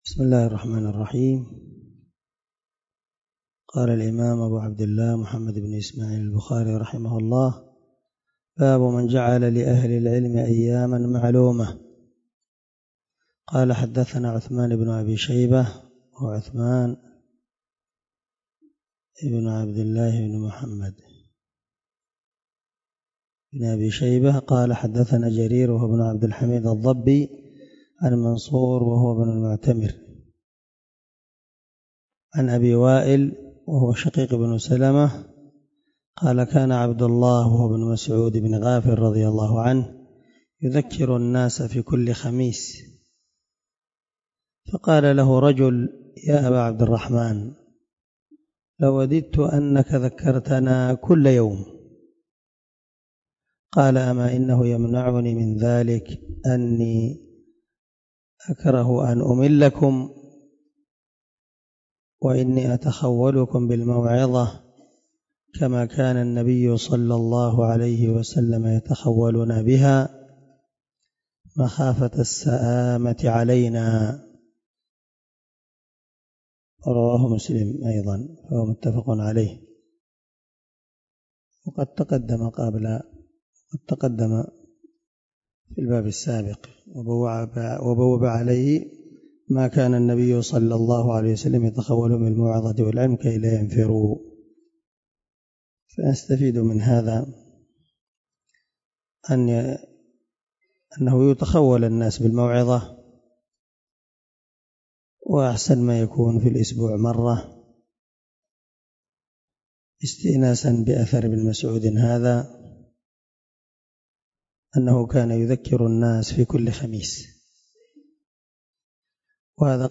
✒ دار الحديث- المَحاوِلة- الصبيحة.